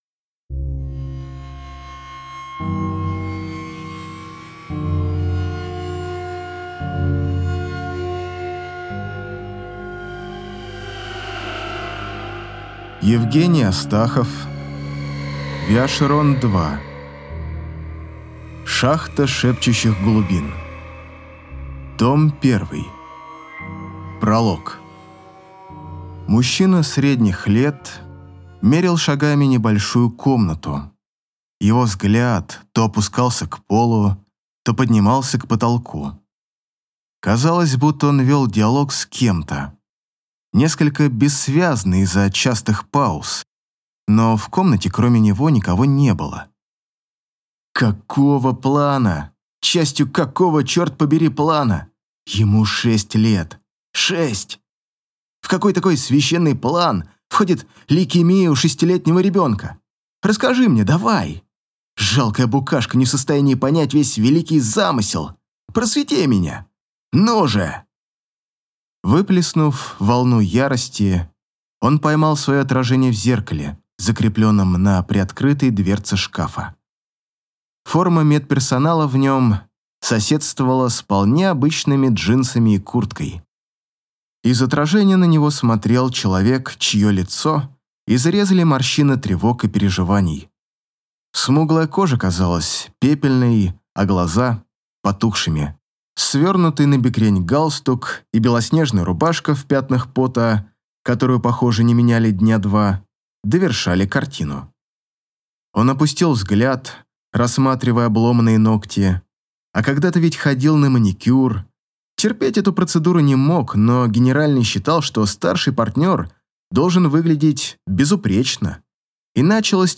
Аудиокнига Шахта Шепчущих Глубин. Том I | Библиотека аудиокниг